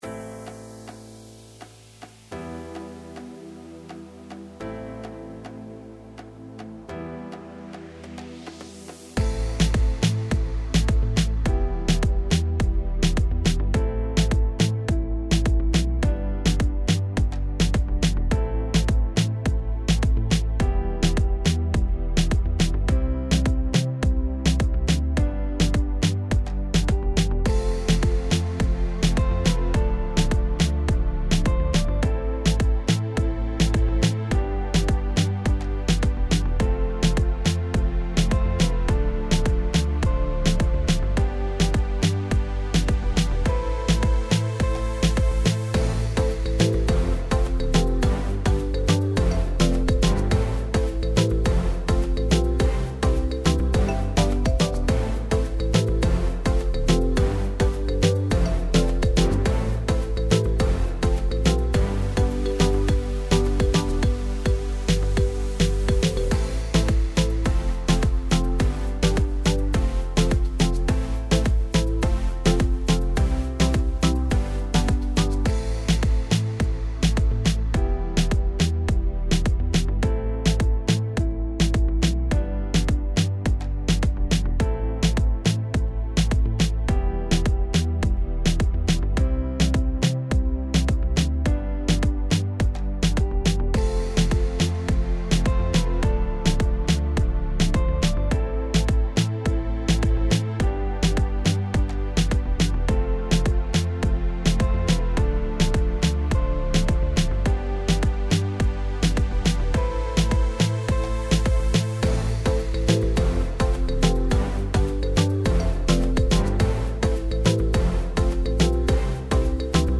МУЗЫКА